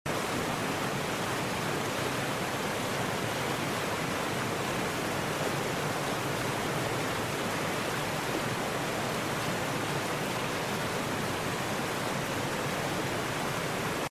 Sitting amongst fallen trees along the mossy riverbank, the sound of rushing water fills the forest air. Just upstream, a beautiful little waterfall tumbles over the rocks, framed by old growth trees and dappled sunlight. The movement of the water is steady and calming, flowing with purpose through the peaceful wilderness.